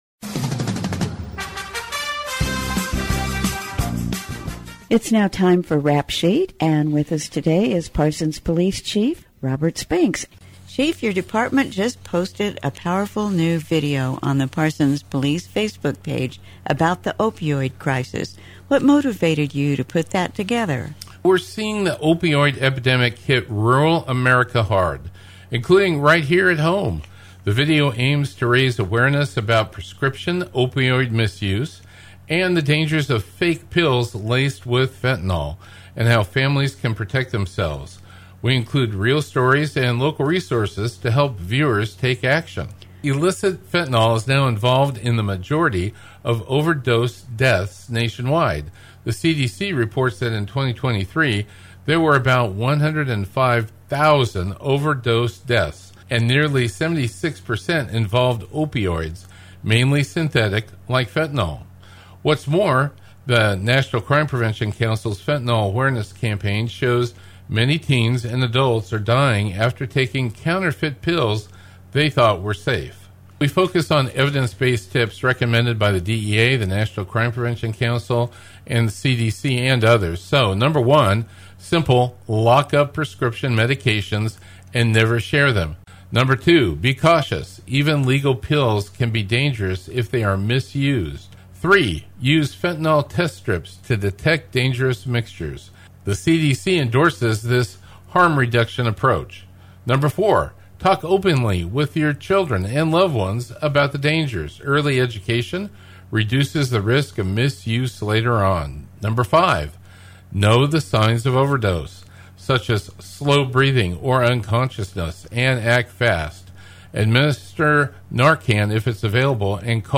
The Rap Sheet will be a reoccurring Podcast with the Parsons Police Chief Robert Spinks, and occasional guests, covering a variety of topics with the community and letting citizens know about upcoming events activities and information. The 'Rap Sheet' is aired on Wednesdays at 7:30am on 106.7 FM and 1540 AM V93KLKC.